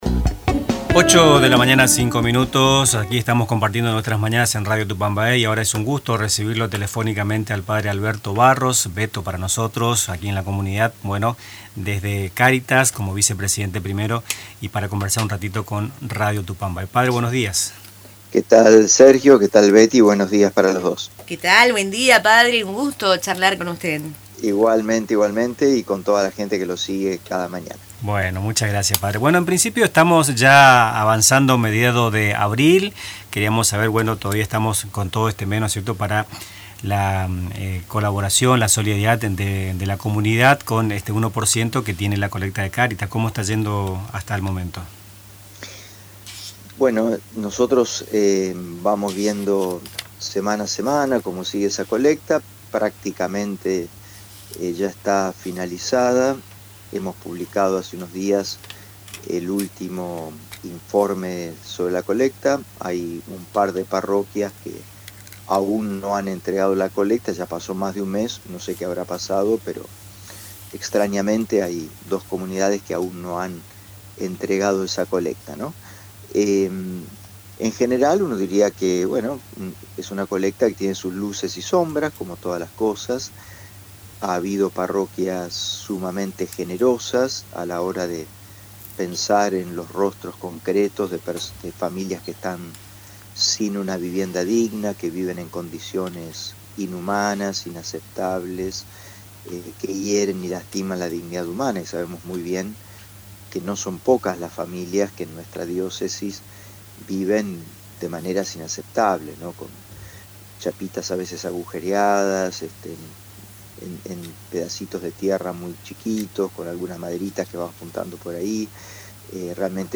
En un diálogo con Radio Tupa Mbae